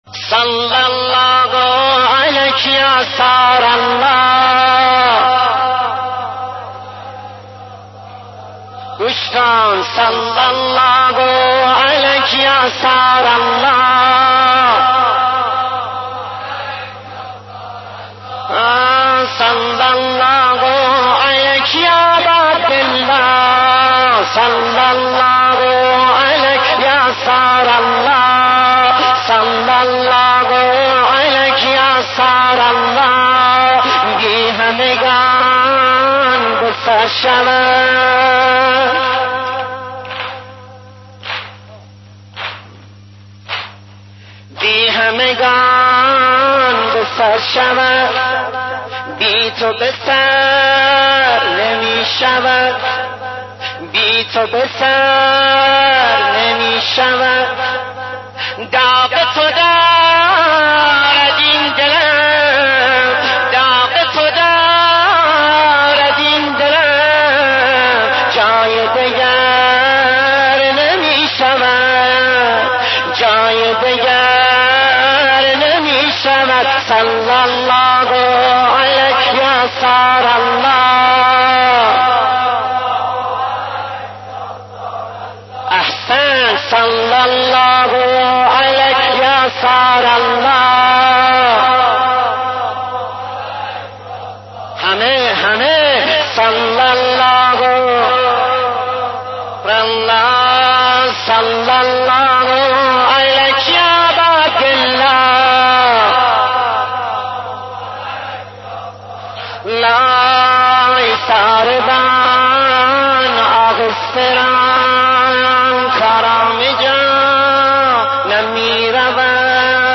مداح اهل بیت استاد